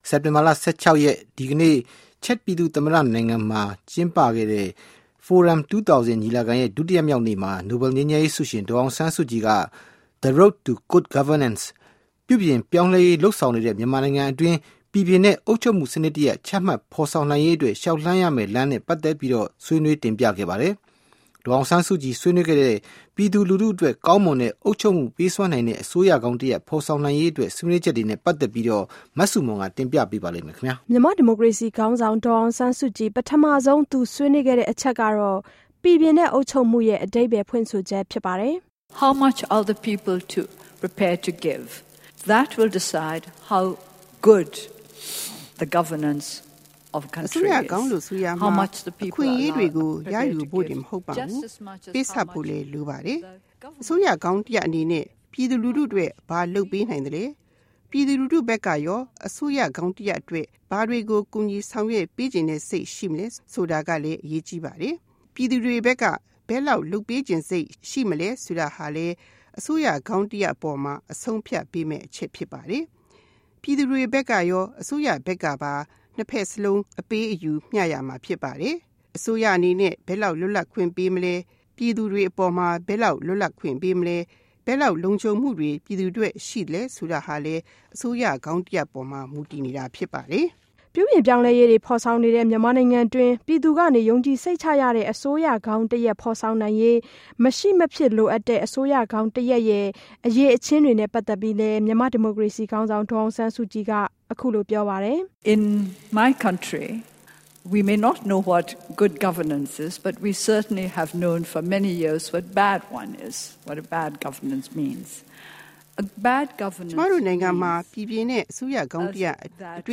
Forum 2000 မှာ ဒေါ်စုမိန့်ခွန်းပြောကြား